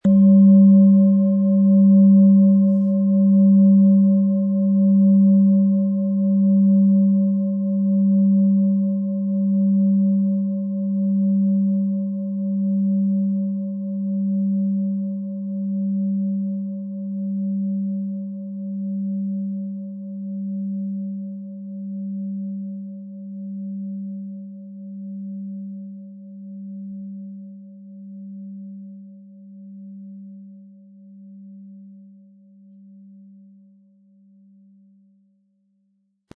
Tibetische Universal-Klangschale, Ø 18,8 cm, 1000-1100 Gramm, mit Klöppel
MaterialBronze